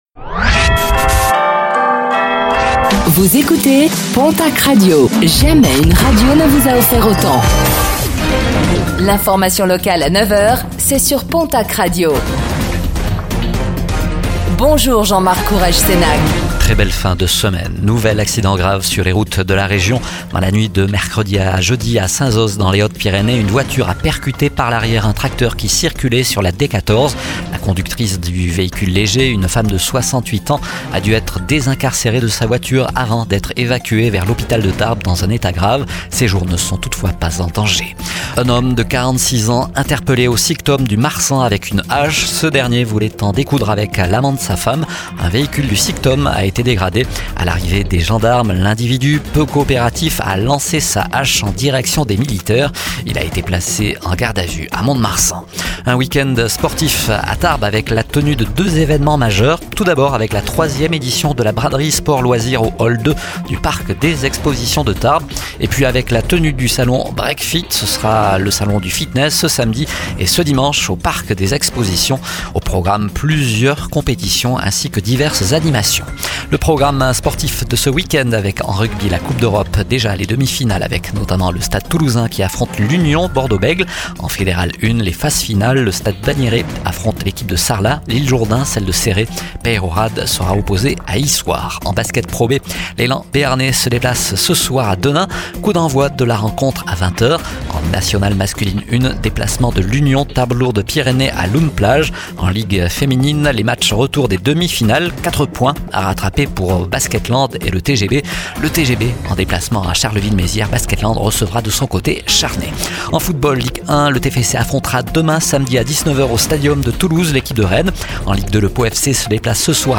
Infos | Vendredi 02 mai 2025